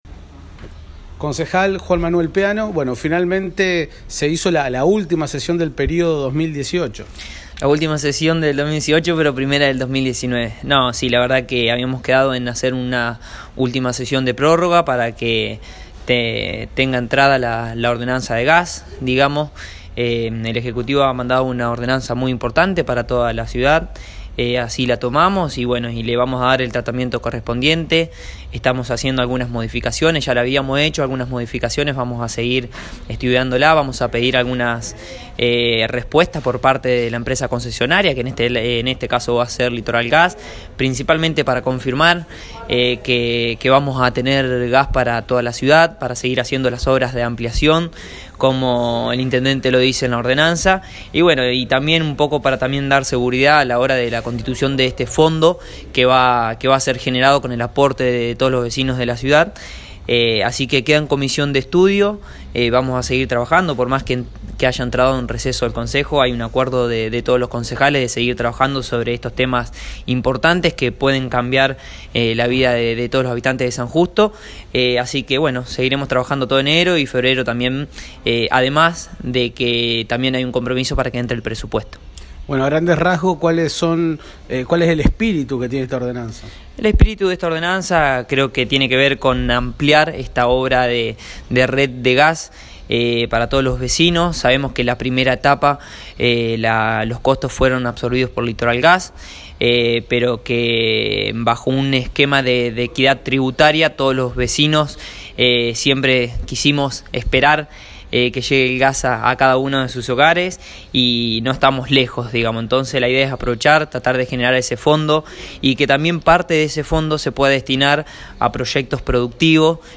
La nota al Concejal Juan Manuel Peano.